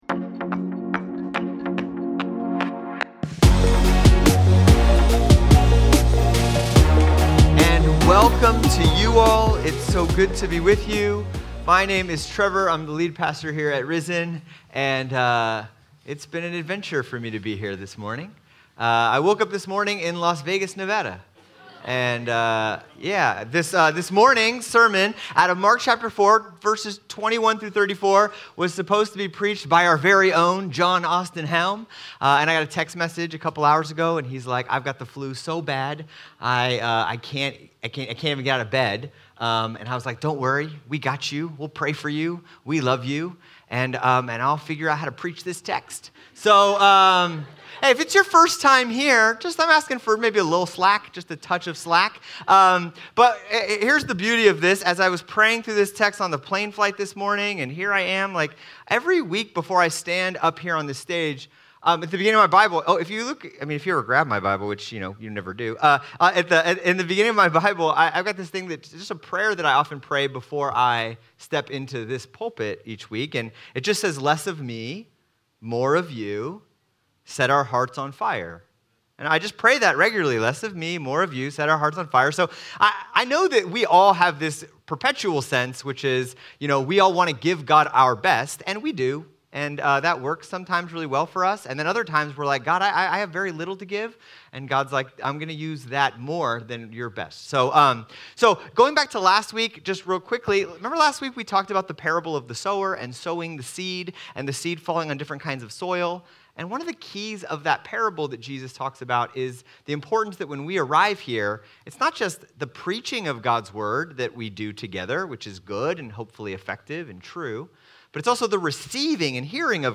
Sermons | RISEN CHURCH SANTA MONICA, INC